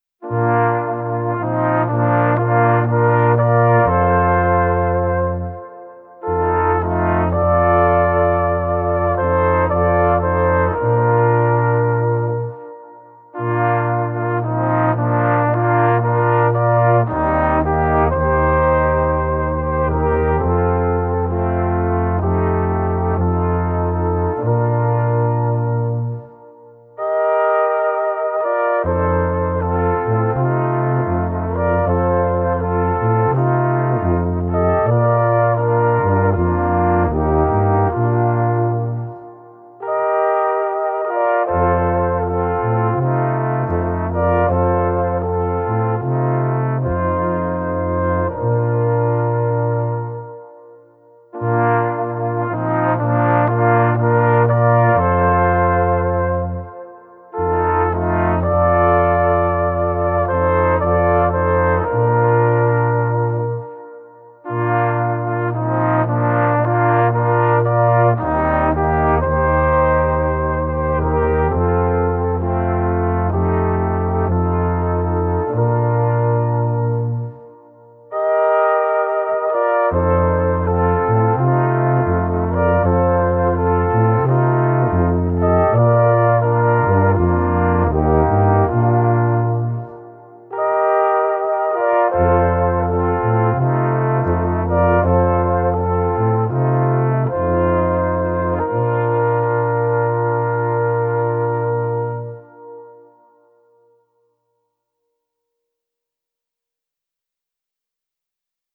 Gattung: Weihnachtliche Weisen für 4 oder 5 Blechbläser